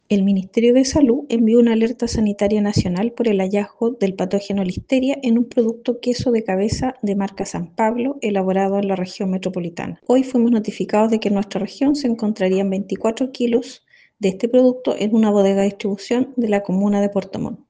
La seremi (s) de Salud de Los Lagos, Sofía Torres, indicó que durante la jornada de ayer se notificó la existencia de estos productos en una bodega en Puerto Montt.